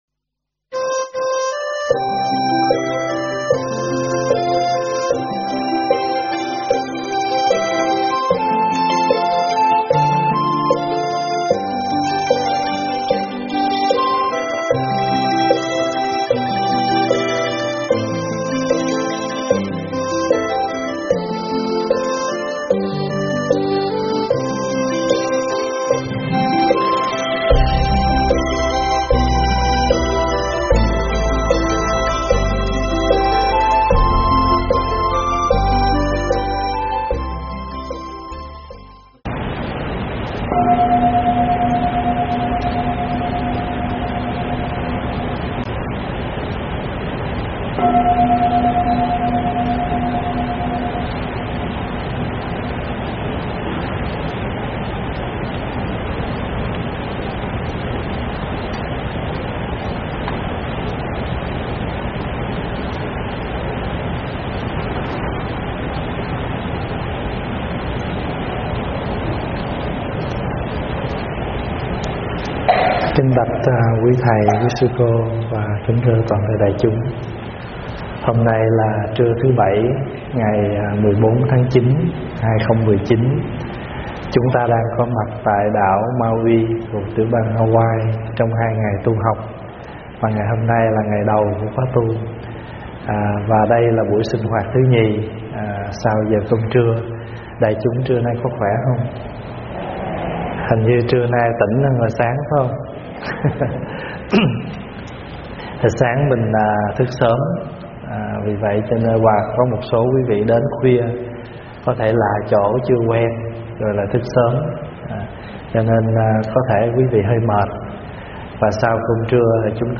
Thuyết pháp
Catholic Church, Mauii, Hawaii